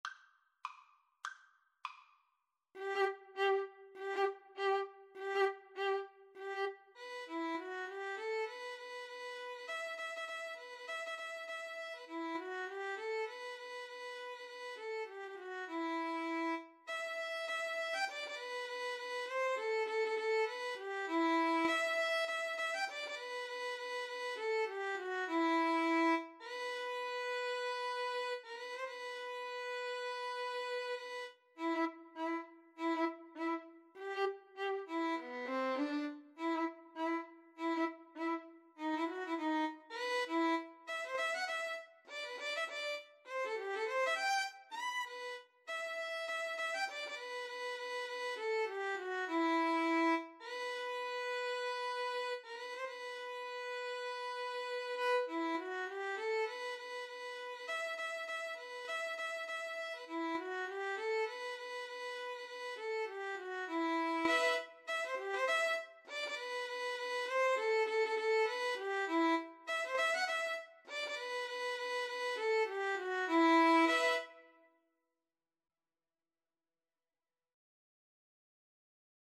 E minor (Sounding Pitch) (View more E minor Music for Violin Duet )
Firmly, with a heart of oak! Swung = c.100